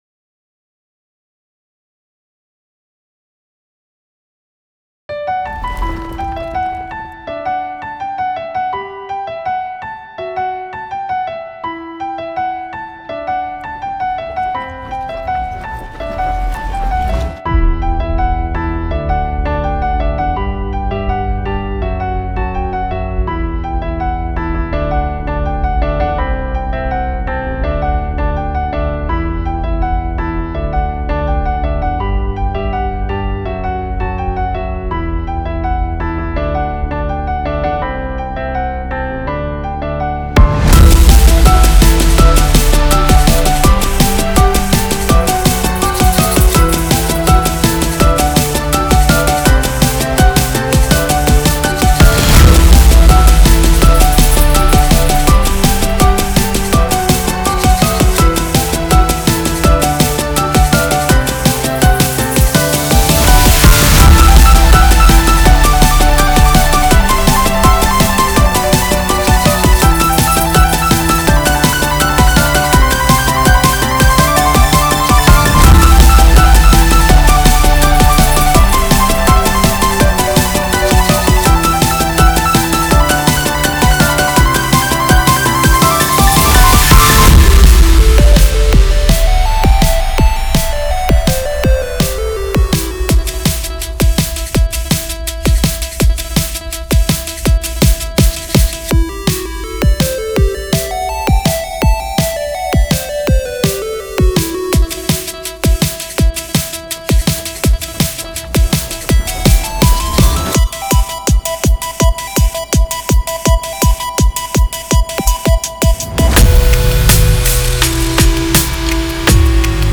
An electronic piece weaving ambient textures through shimmering arpeggios and deep bass foundations.